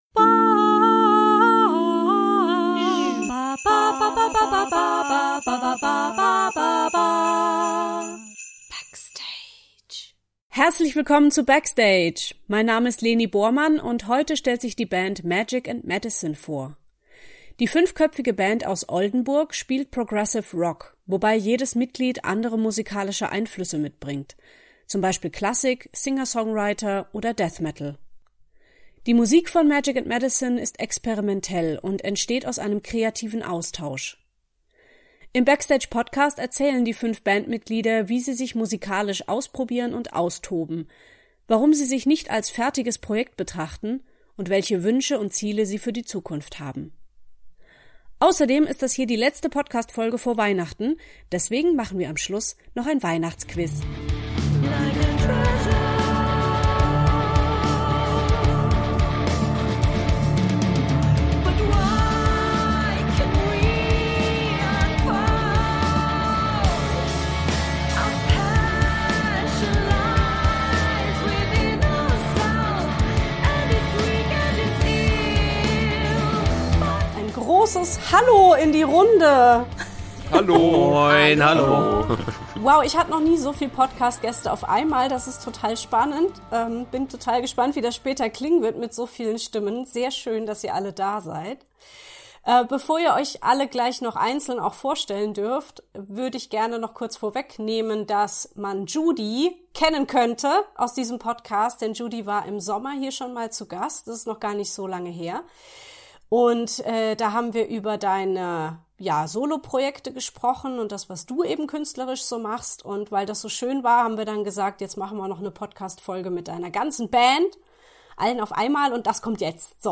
Im Backstage Podcast erzählen die fünf Bandmitglieder, wie sie sich musikalisch austoben, warum sie sich nicht als fertiges Projekt betrachten und welche Wünsche und Ziele sie für die Zukunft haben. Außerdem ist das hier die letzte Podcast Folge vor Weihnachten, deswegen machen wir am Schluss noch ein Weihnachts-Quiz.